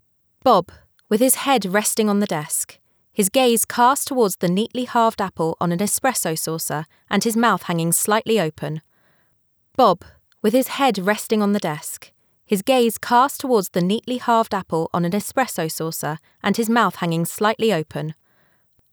And all the nuclear SS sounds settled down.
Your clip is at -64.5dB and has well-behaved gentle shshshshsh rain in the trees noise, so I’m calling it good.
The voice seems theatrically to be OK.